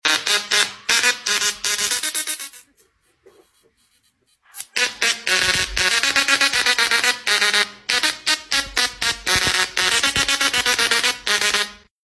ElectroSound Tesla Plasma Speaker 🎧🌟 sound effects free download